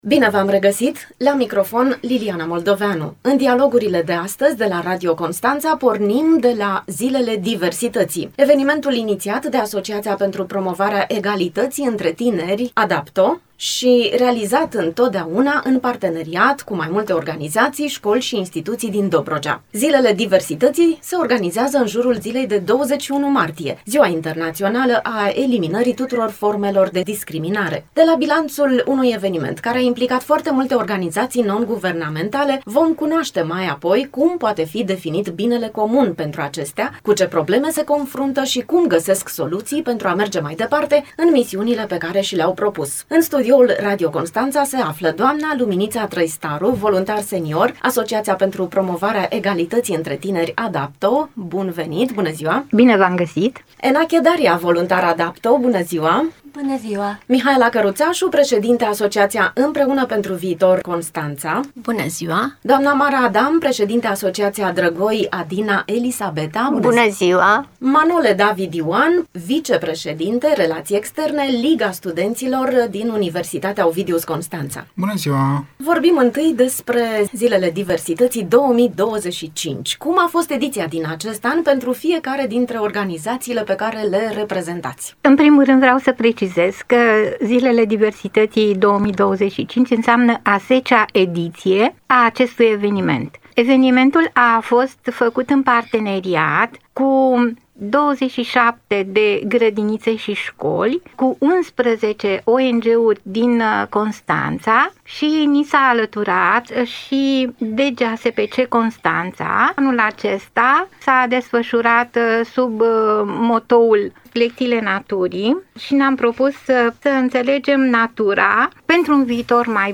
cu reprezentanții unor ONG-uri foarte active în acest moment despre proiectele lor de voluntariat